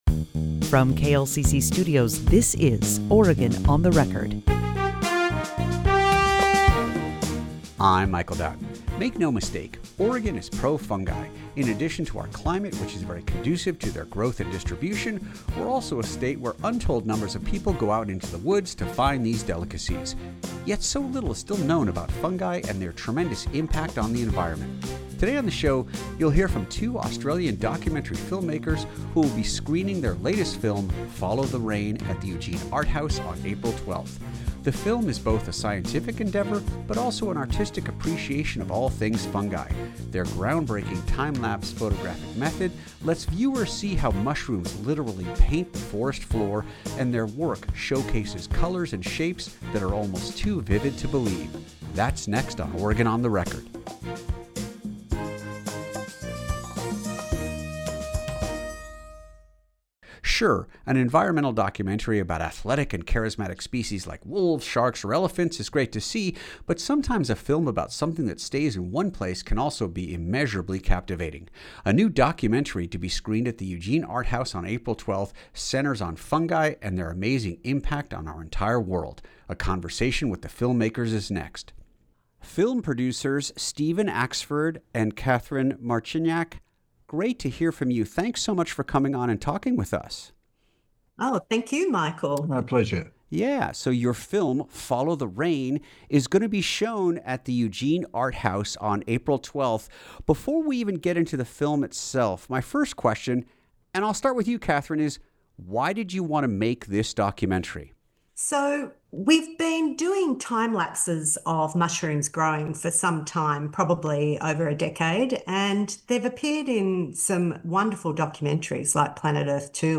On Oregon On The Record we bring you interviews and conversations about issues that matter most to western and central Oregon.